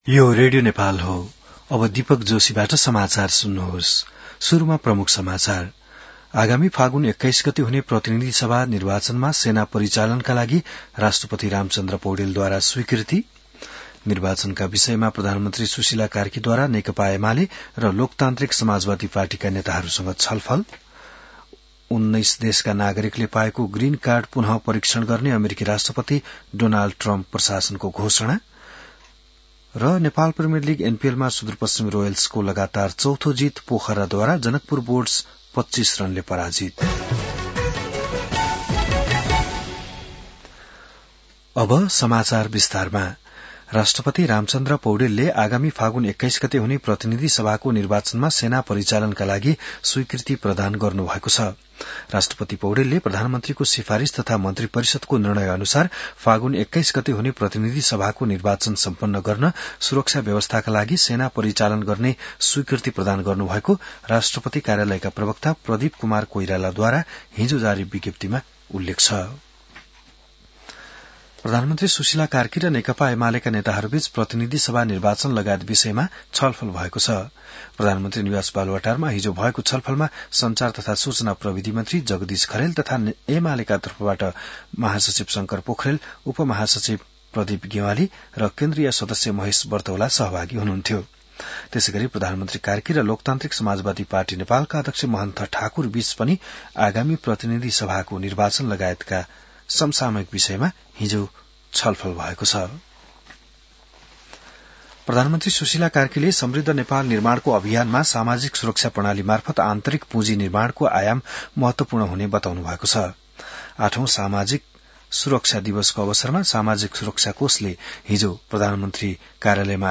बिहान ९ बजेको नेपाली समाचार : १२ मंसिर , २०८२